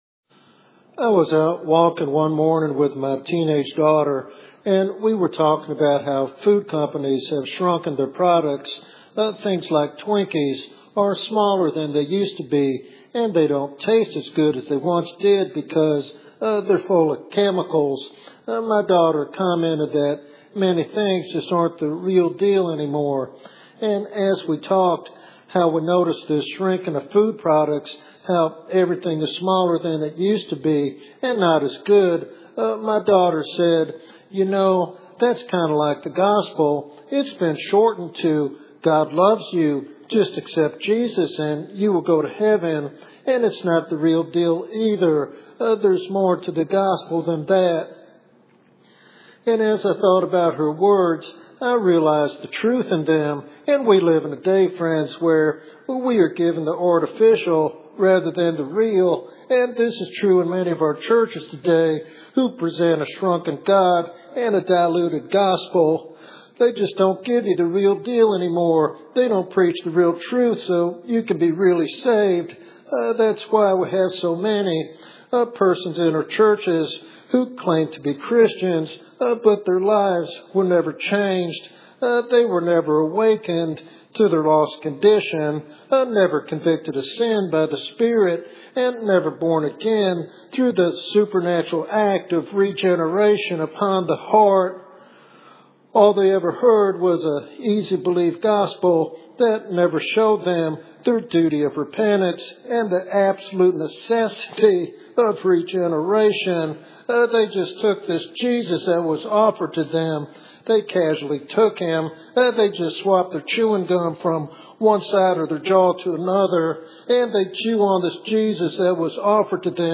This sermon is a powerful reminder of the authentic gospel’s power to awaken hearts and reconcile sinners to God.